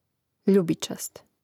ljȕbičast ljubičast prid.